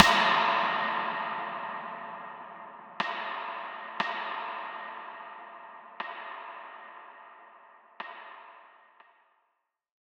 Big Drum Hit 13.wav